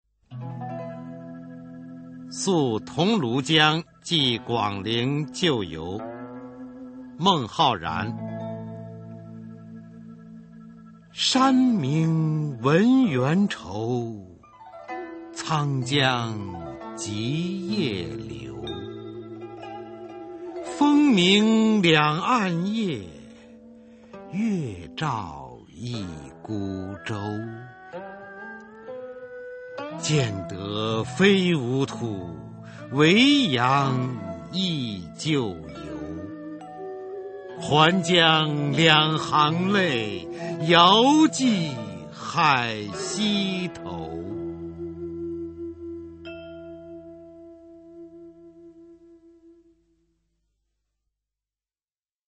[隋唐诗词诵读]孟浩然-宿桐庐江寄广陵旧游 配乐诗朗诵